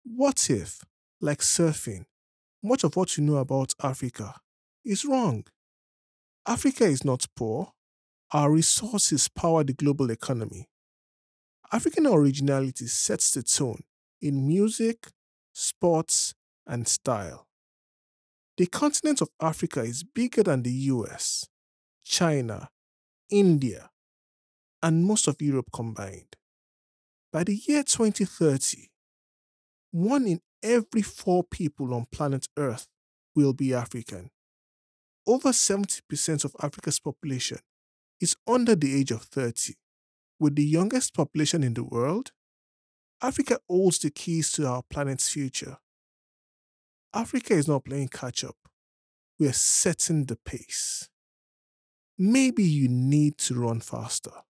Male
Adult (30-50)
Narration
This File Is A Narration Demo